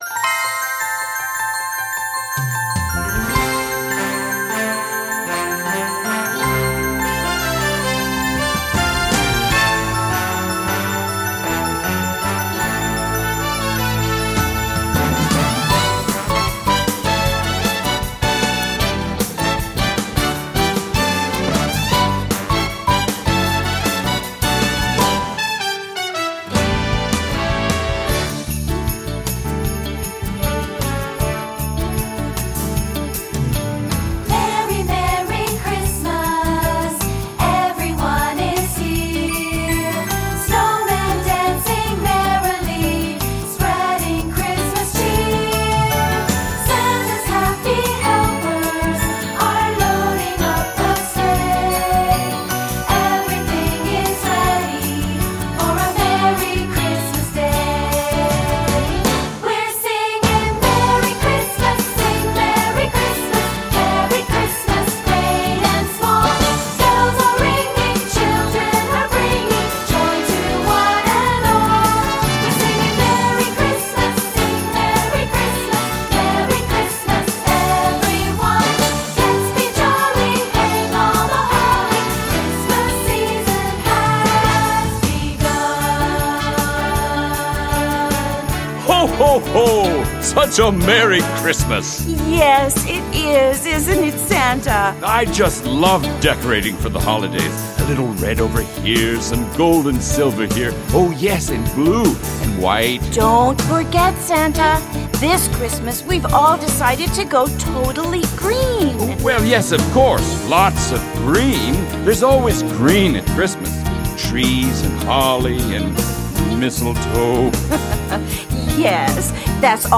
With Singing and dialogue: